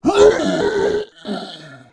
Index of /App/sound/monster/orc_black
dead_1.wav